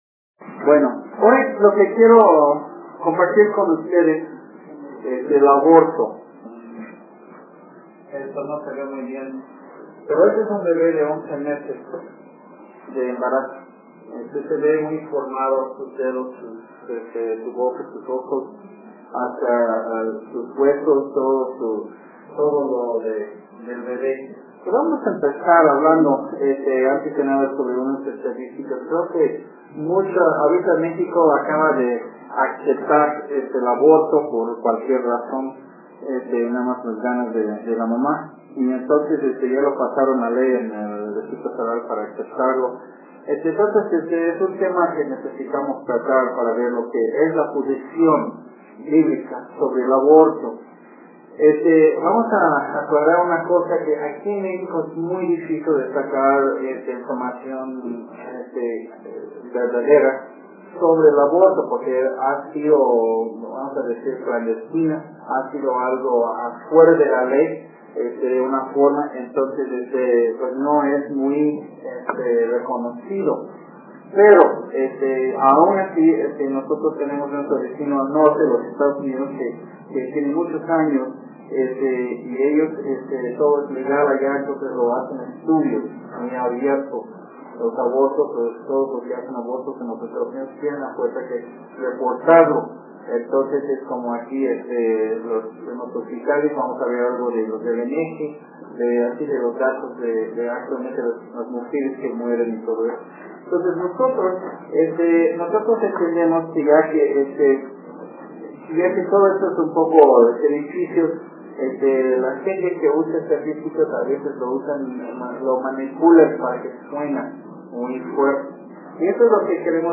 Un sermón y presentación sobre la Biblia en contra del Aborto. Esto es especialmente con datos del aborto en México con principios bíblicos.